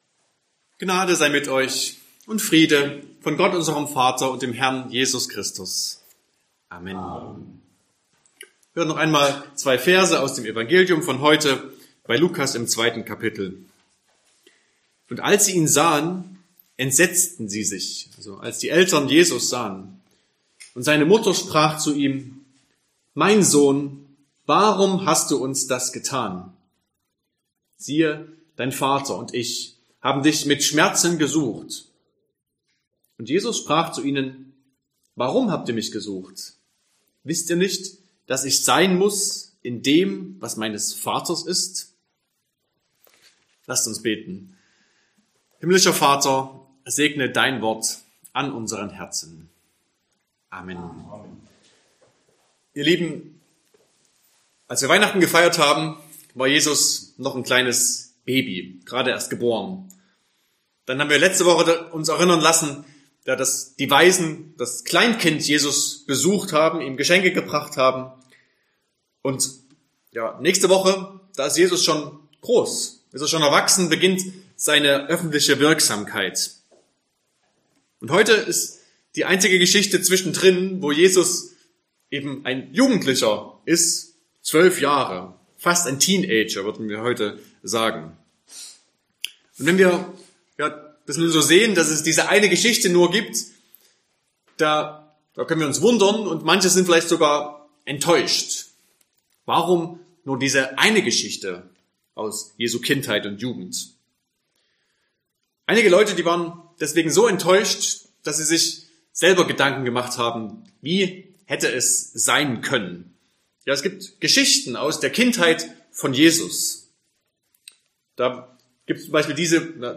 Sonntag nach Epiphanias Passage: Lukas 2, 41-52 Verkündigungsart: Predigt « 1.